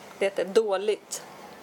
悪い(WARUI) 　　 It is not good.／It is bad. Det är dåligt (ドアッリット)